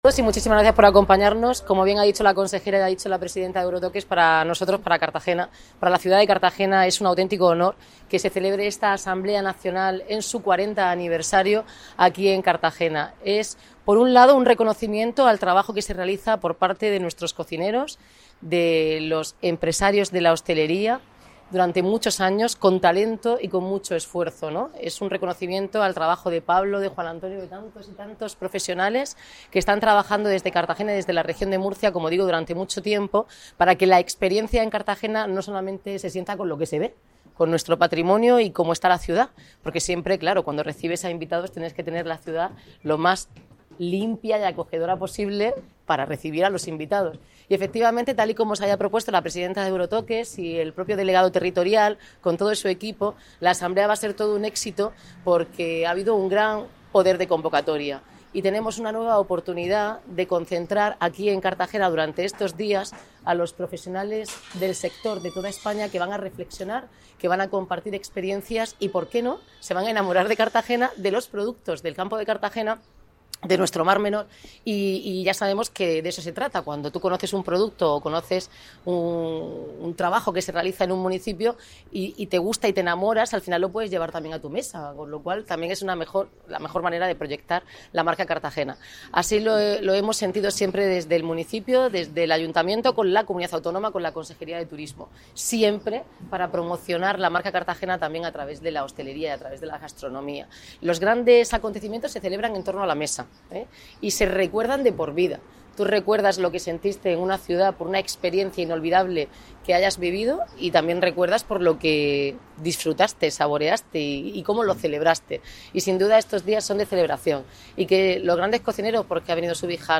Declaraciones de Noelia Arroyo
Cartagena se ha convertido hoy, lunes 20 de abril, en el punto de encuentro de la alta cocina española con la inauguración de la XIV Asamblea Nacional Euro-Toques, que reúne en el Auditorio y Palacio de Congresos El Batel a los principales referentes de los fogones españoles coincidiendo con el 40 aniversario de la organización.